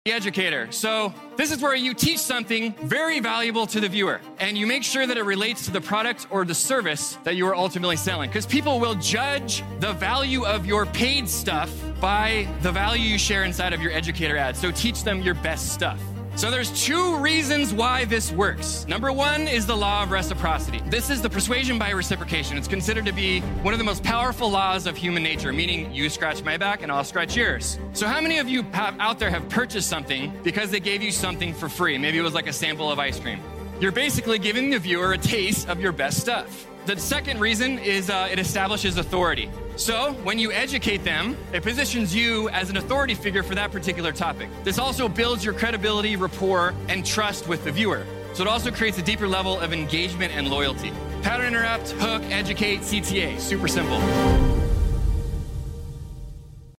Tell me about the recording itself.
This was a presentation I delivered in front of 5,000 entrepreneurs and marketers in September of 2023.